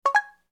Звуки съемки видео
Звук первого кадра видеозаписи на телефоне